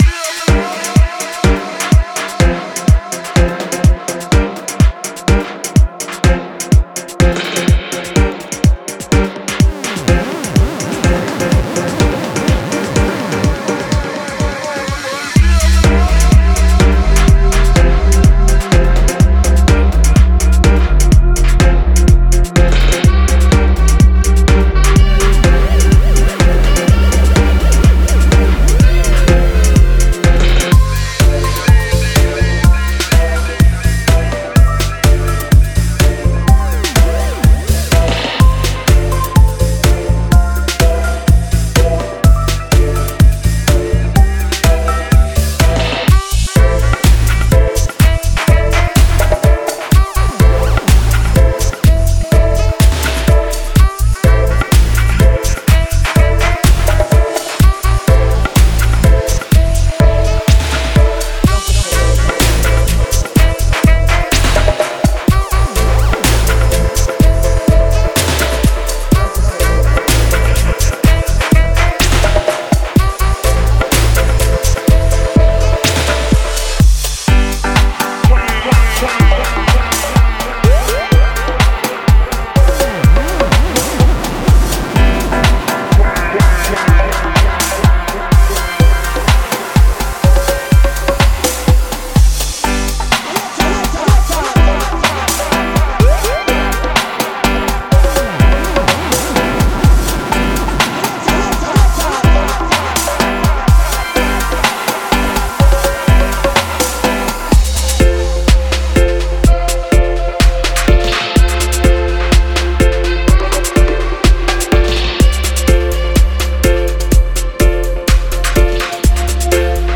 Genre:Dub
音色はシームレスに進化し、起源に忠実でありながら新しい音風景へと音楽を導きます。
デモサウンドはコチラ↓